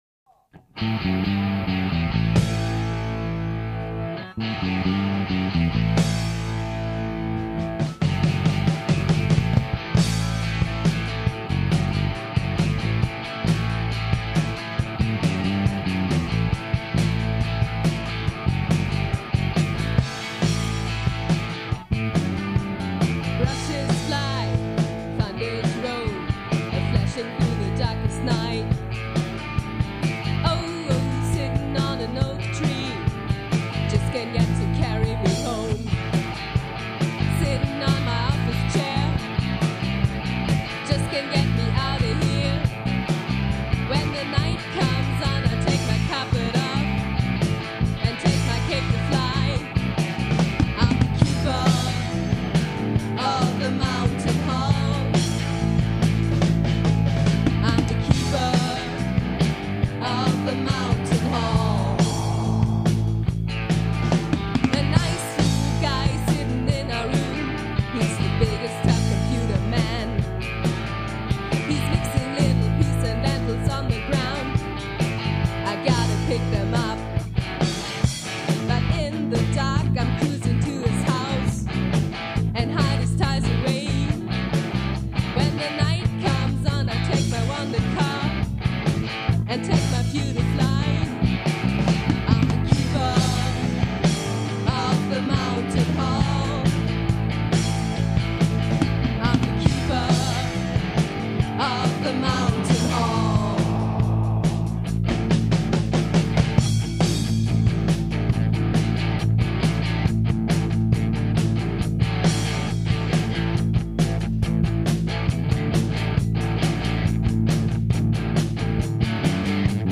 Baß
Schlagzeug
Gesang
Background-Gesang, Percussion
Gitarre, Gesang